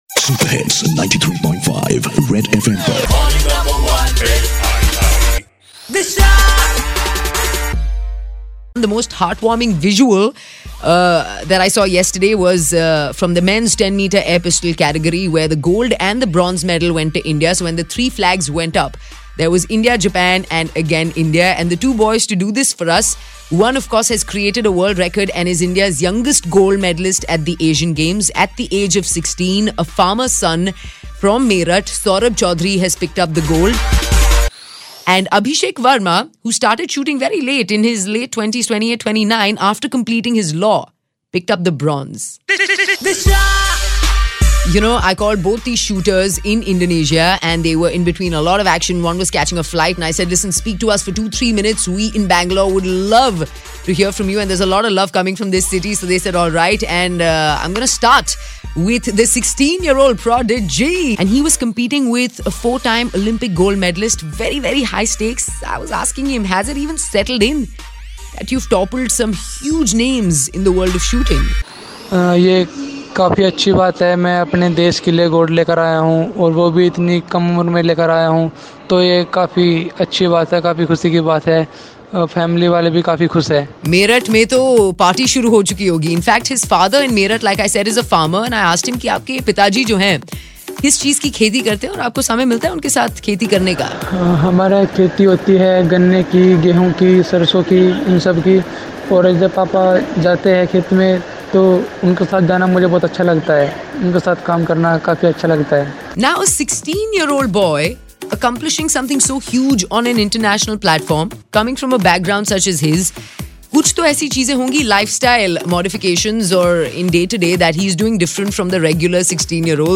Live from Indonesia youngest Gold medalist Saurabh at Asian Games 2018